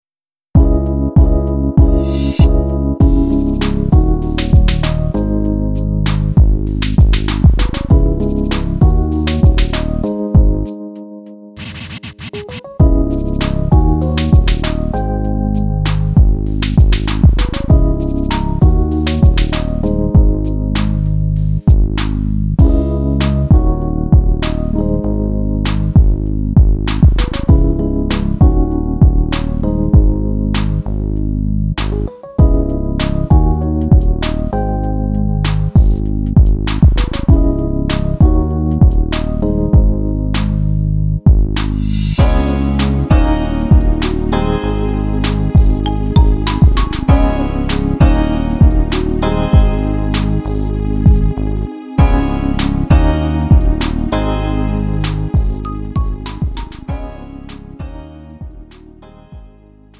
음정 원키 3:39
장르 가요 구분 Lite MR